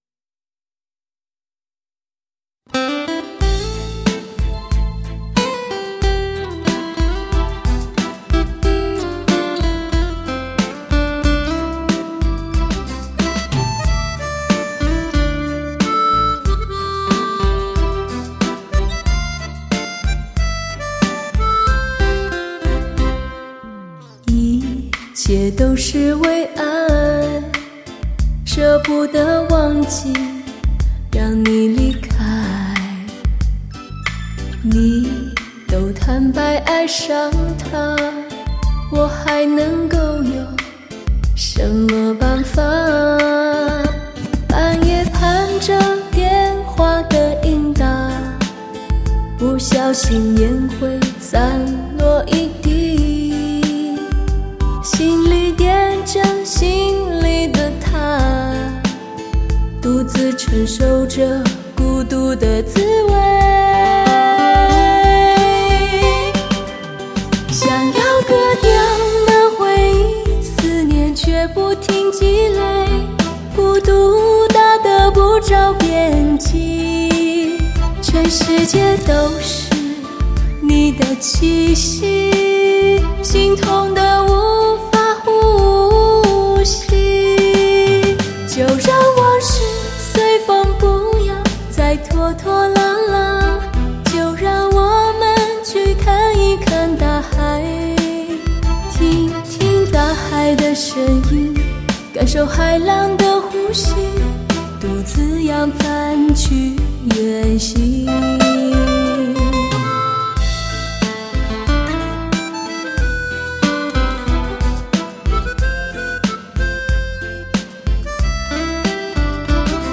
她那委婉自然的唱腔犹如空中的飞鸟般柔美。
碟中女声定位准确，乐器音色靓绝，值得细意品尝。